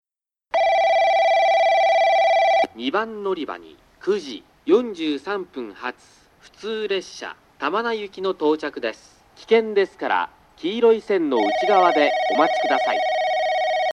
なお駅前は交通量が多いので、雑音が入りやすいです。
2番のりば接近放送（玉名行き）